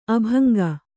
♪ abhaŋga